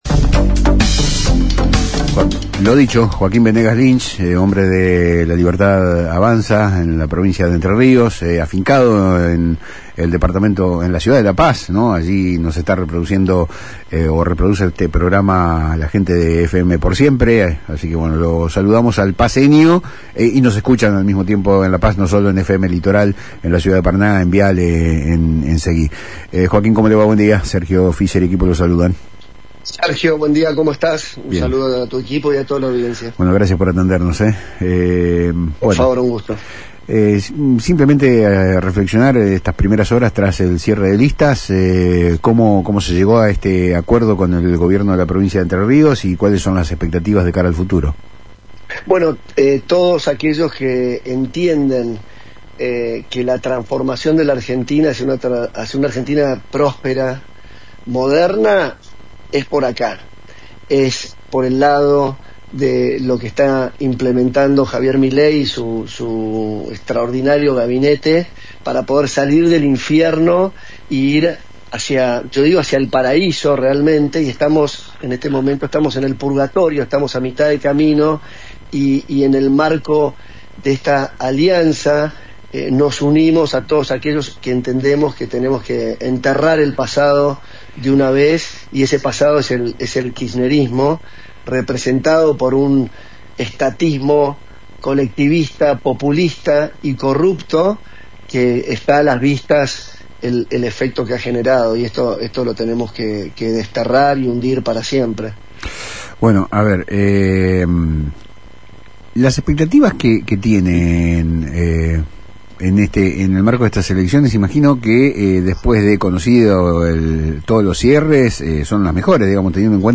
entrevista
en Palabras Cruzadas por FM Litoral de Paraná